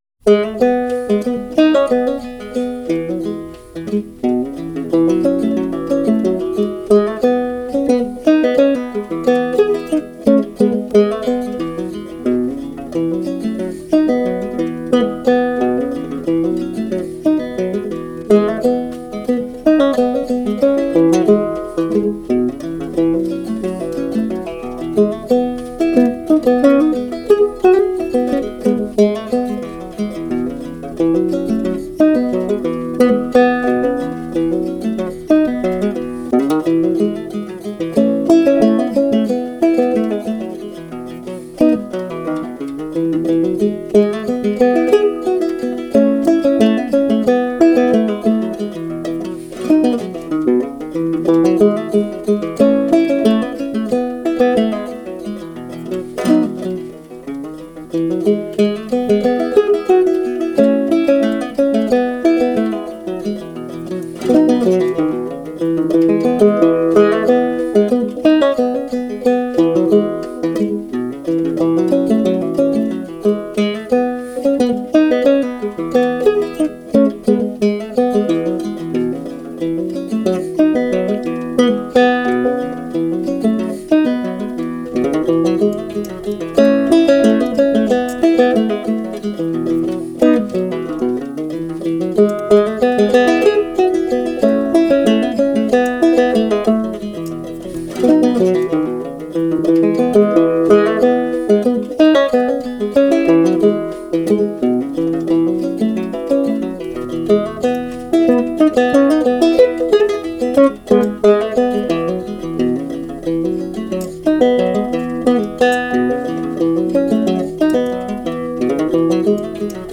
An original tune
Banjo Hangout Newest 100 Clawhammer and Old-Time Songs